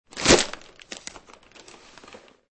Descarga de Sonidos mp3 Gratis: golpe arruga.
descargar sonido mp3 golpe arruga